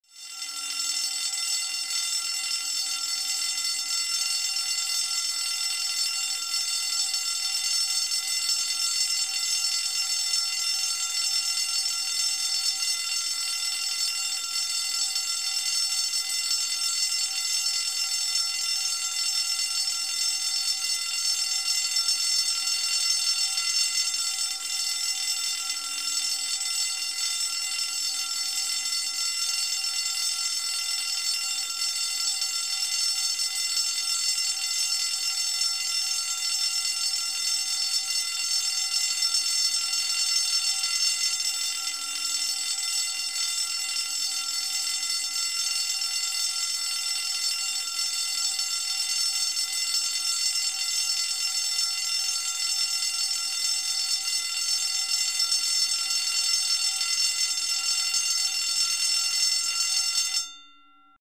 Звуки сирены